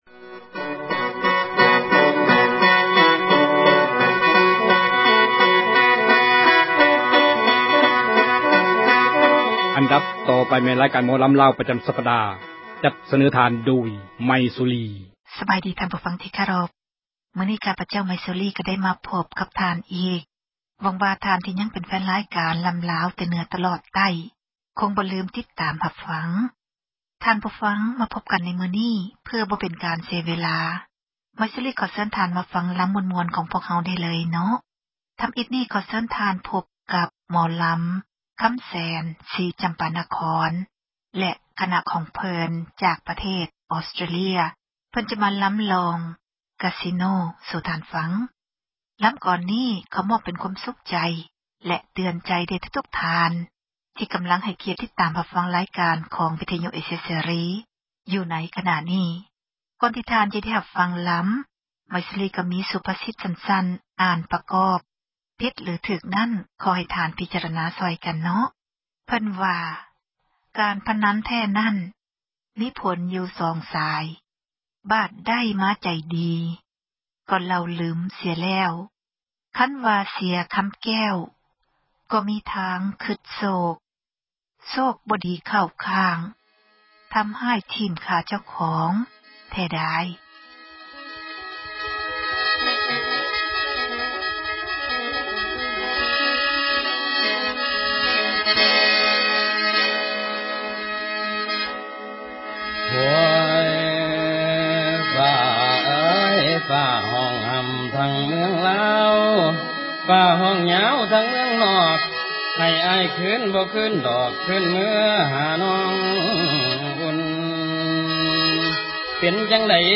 ຣາຍການ ໝໍລໍາ ແຕ່ເໜືອ ຕລອດໃຕ້ ຈັດມາສເນີທ່ານ